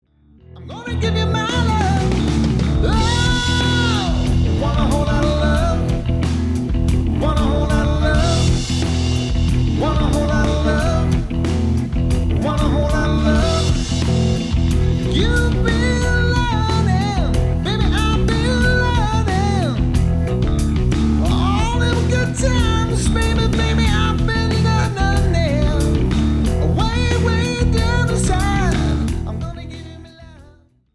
Voicing: Guitar w/D